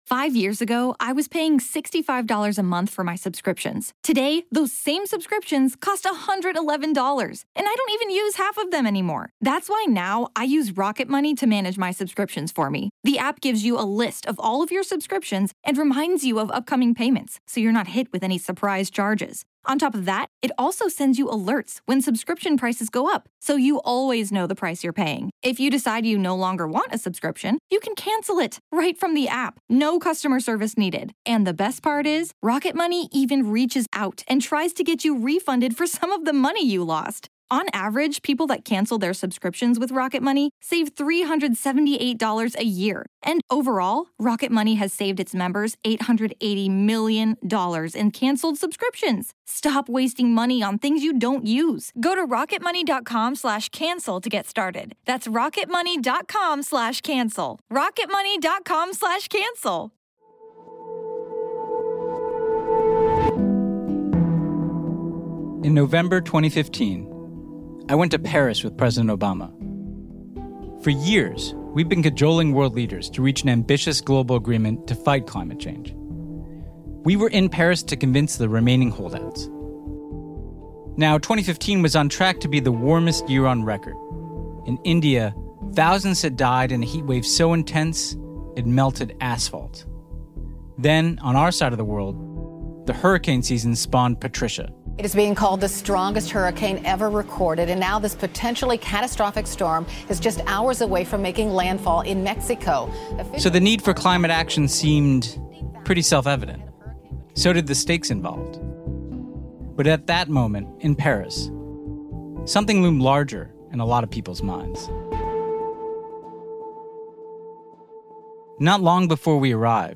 Host Ben Rhodes talks to former Australian Prime Minister Kevin Rudd about the roadblocks he faced from Rupert Murdoch and the fossil fuel industry, and activists from the Pacific Islands to Europe to the US about what needs to be done.